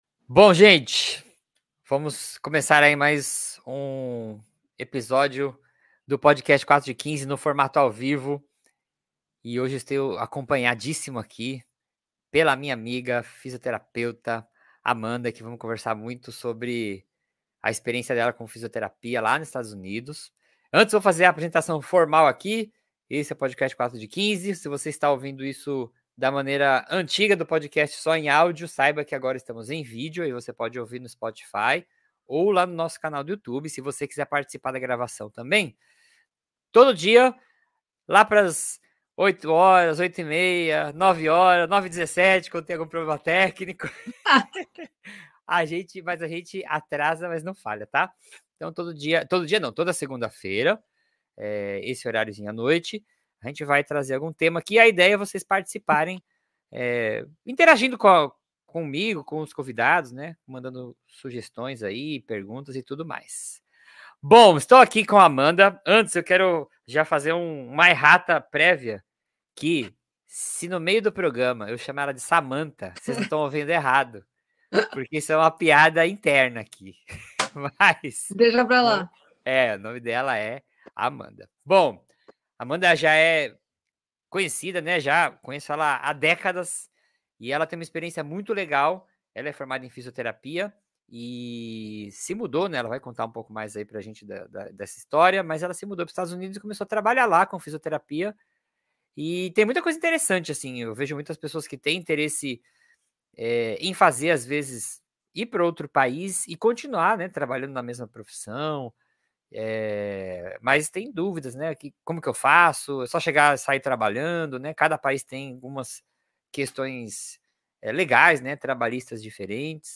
Neste episódio exclusivo do nosso podcast ao vivo